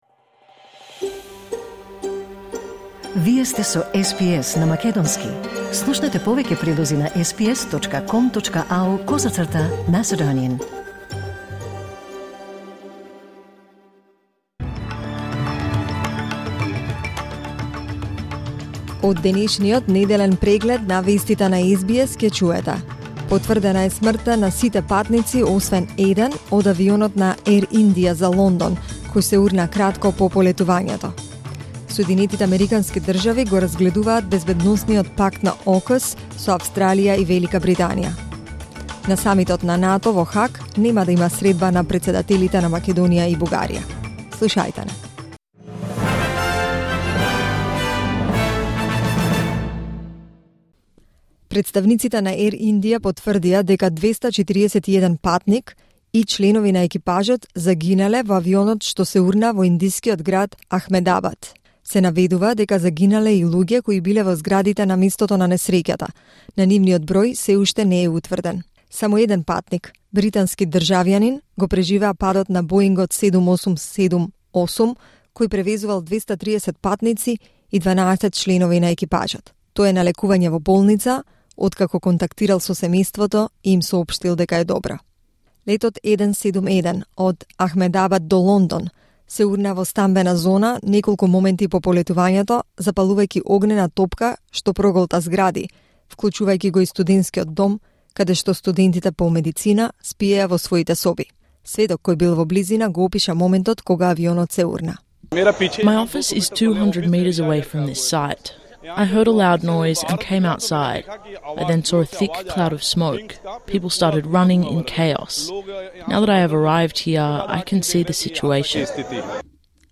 Вести на СБС на македонски 13 јуни 2025